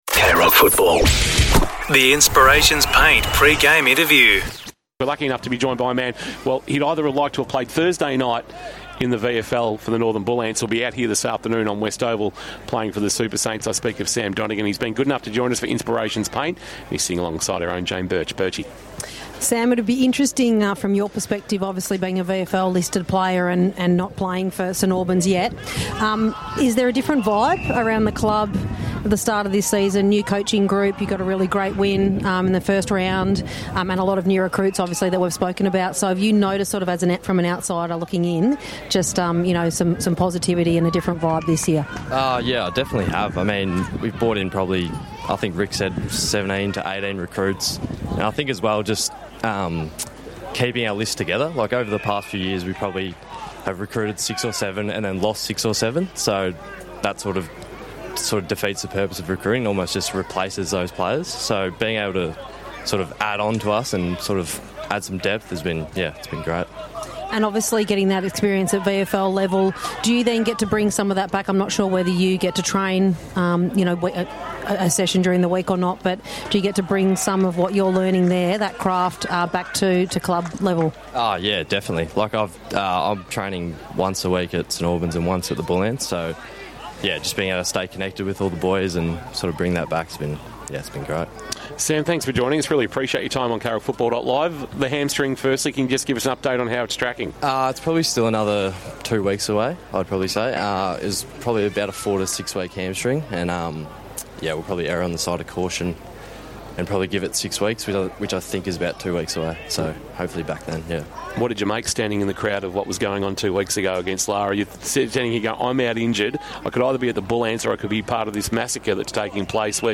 2025 - GFNL - Round 2 - Geelong West vs. St Albans: Pre-match interview